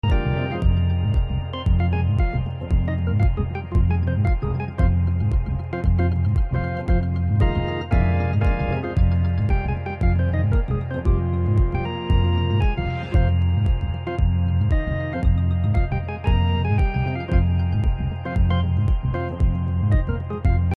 went Mp3 Sound Effect Did a live stream, went well. I love jamming.